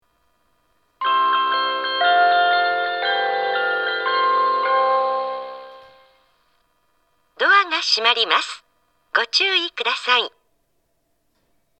スピーカーはすべてユニペックスマリンです。
発車メロディー
一度扱えばフルコーラス鳴ります。